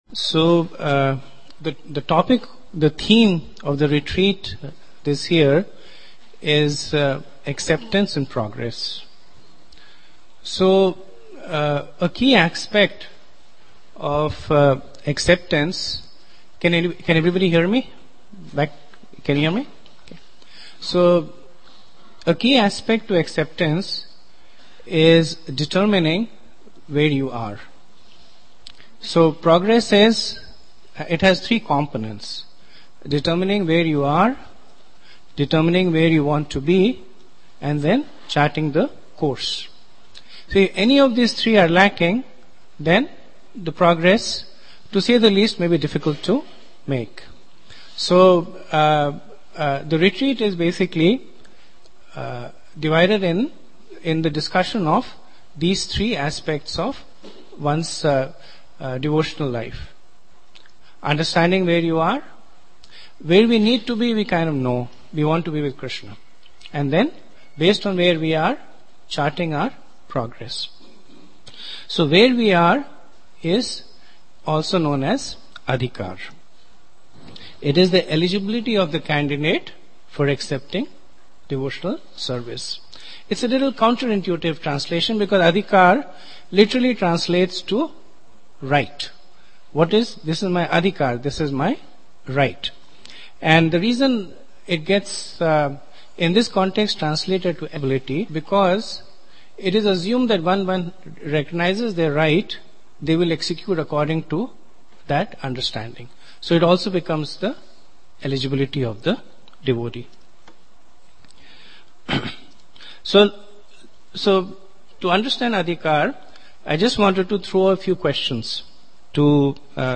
Being Fixed According to Ones Adhikara – Play talk and workshop